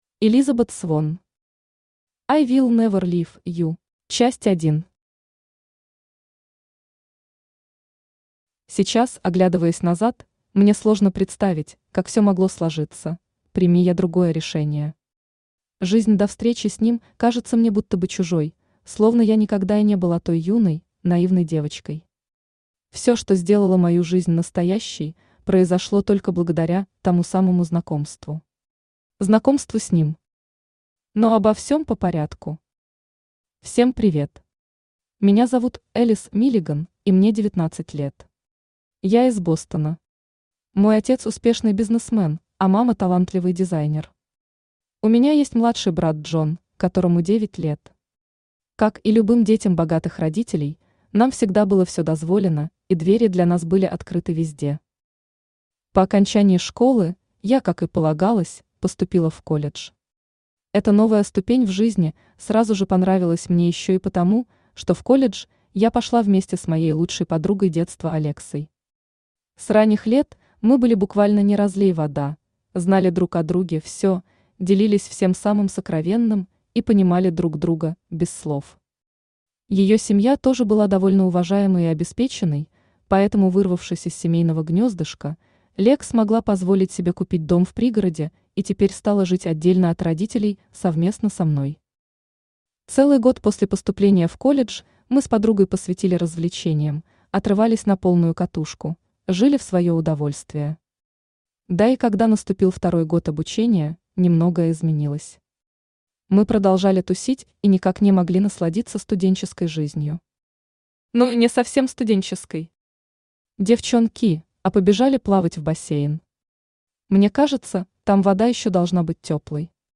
Автор Элизабет Свонн Читает аудиокнигу Авточтец ЛитРес.